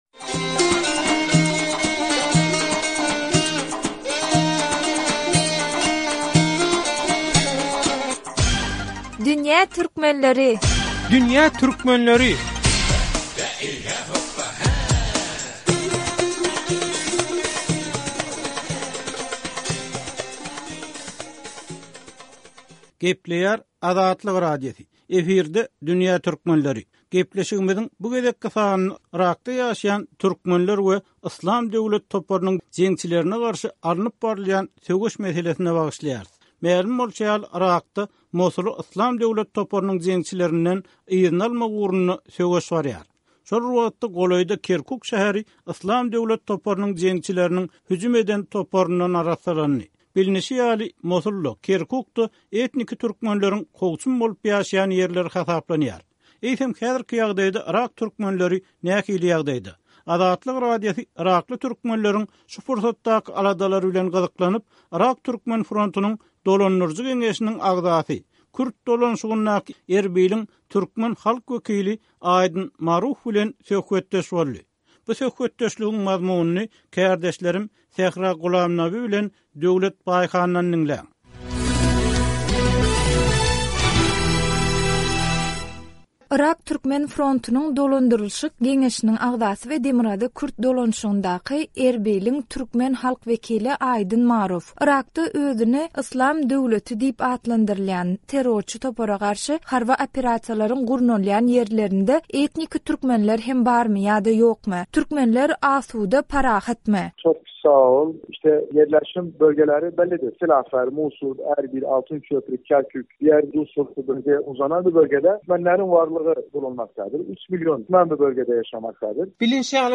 Azatlyk radiosy yrakly türkmenleriň şu pursatdaky aladalary bilen gyzyklanyp, Yrak Türkmen frontynyň dolandyryjy geňeşiniň agzasy, Kürt dolanyşygyndaky Erbiliň türkmen halk wekili Aýdyn Maruf bilen söhbetdeş boldy.